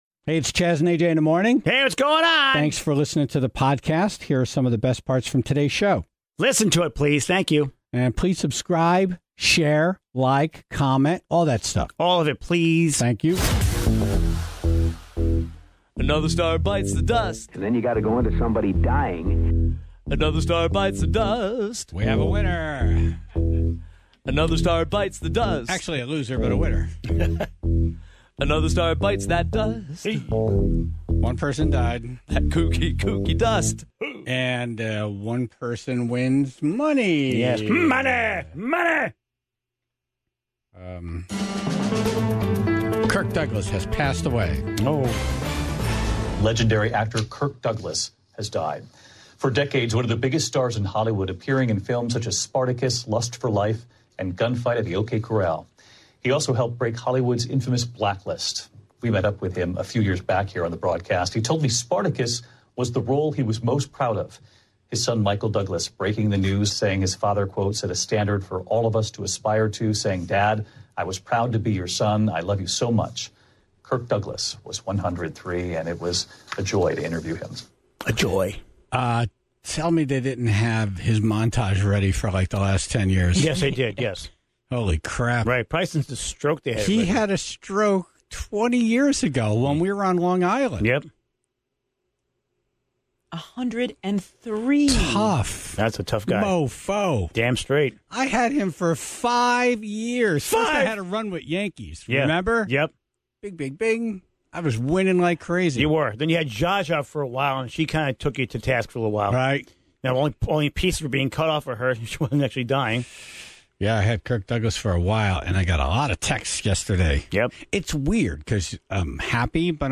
The Tribe calls in their nominations, and an argument breaks out over Dick Van Dyke (0:00)